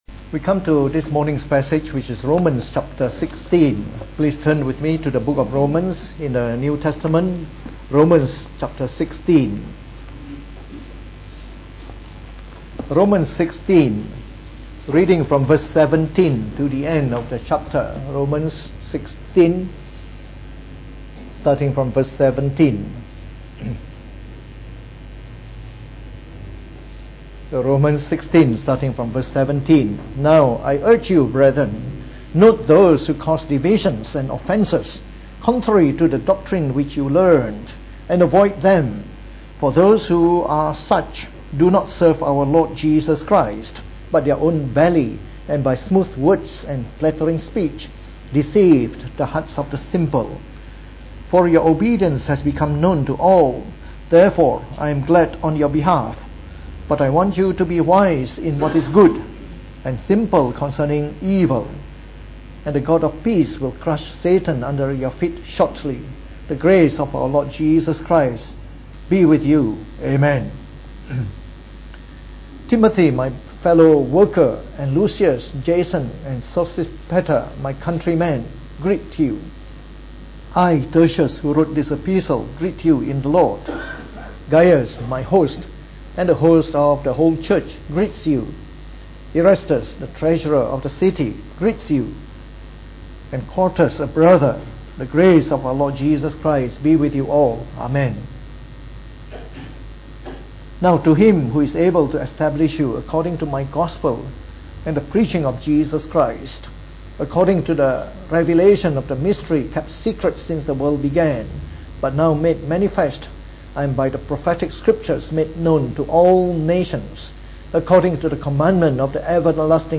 Preached on the 11th of November 2018.